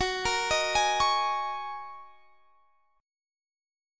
Listen to Gb6add9 strummed